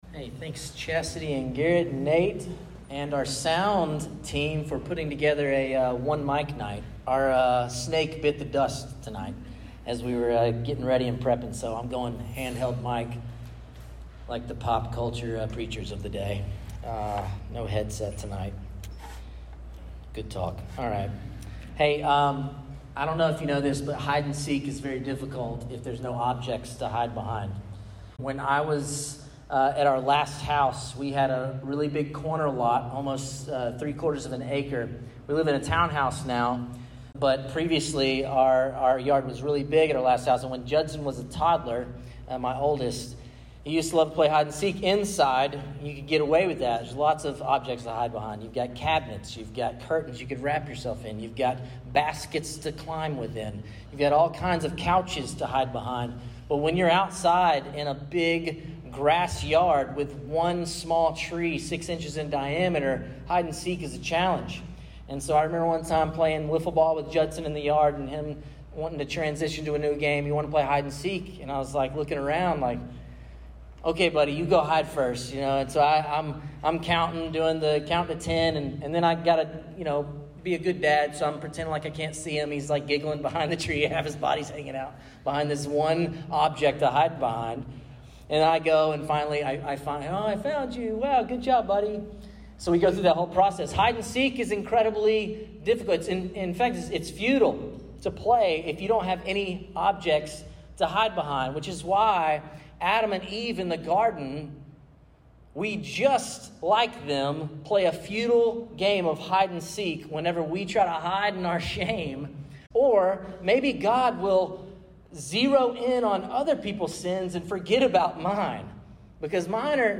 City View Church - Sermons